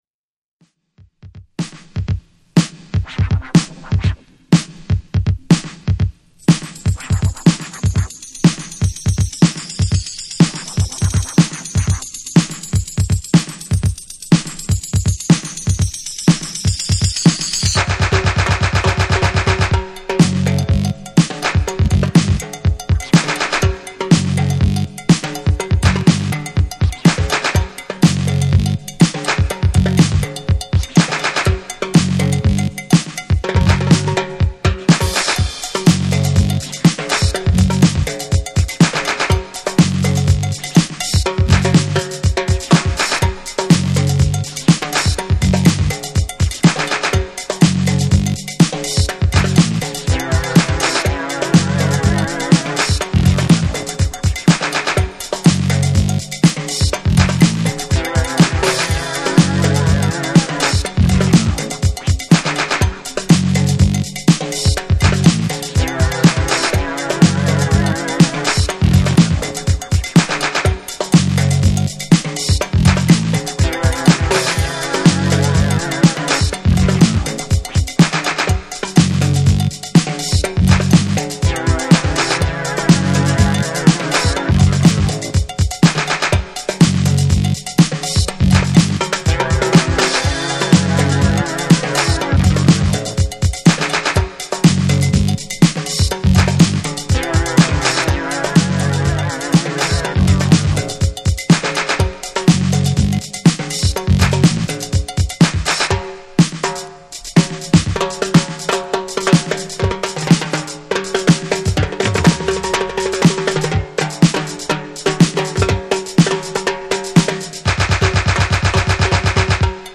Disco Funk